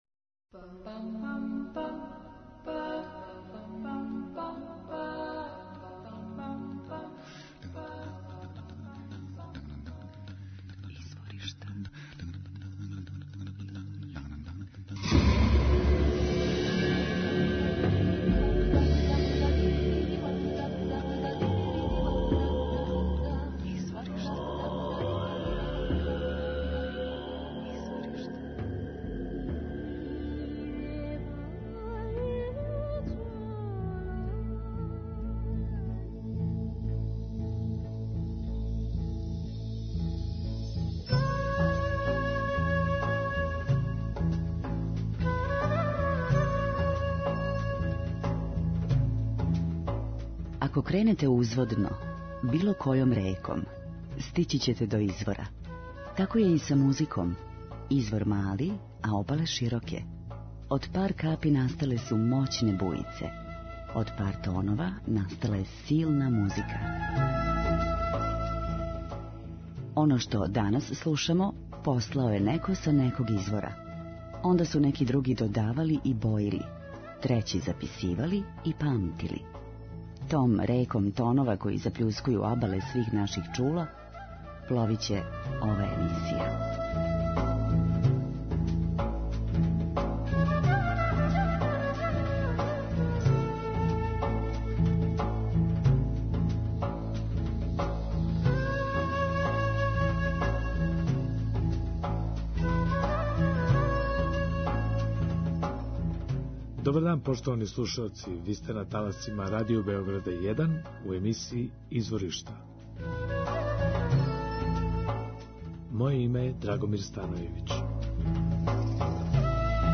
Музика удаљених крајева планете, модерна извођења традиционалних мелодија и песама, културна баштина најмузикалнијих народа света, врели ритмови...
Групо Локито (Grupo Lokito) комбинује блиставе савремене конгоанске мелодије и ритмове, са ватром кубанске музике.